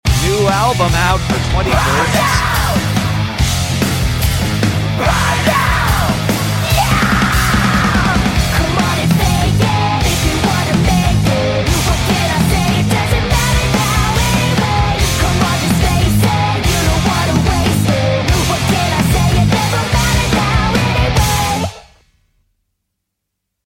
LOUD, FAST music that sounds a little retro